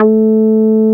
P MOOG A4P.wav